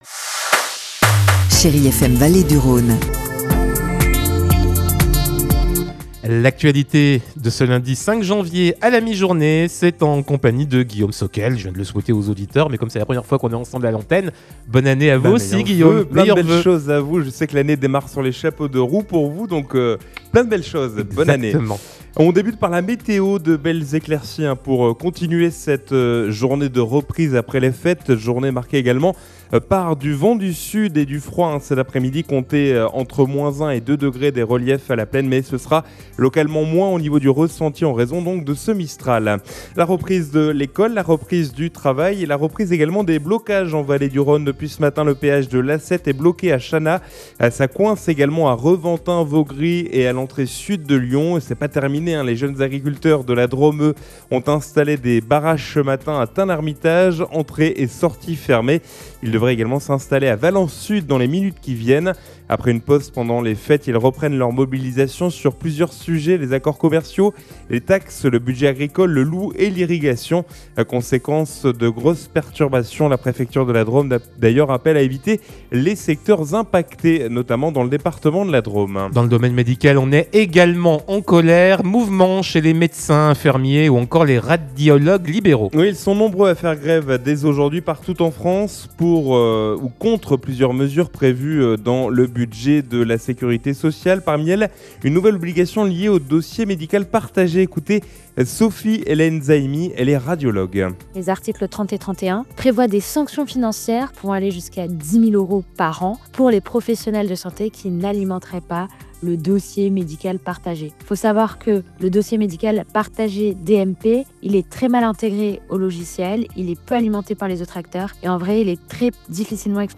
Lundi 5 janvier : Le journal de 12h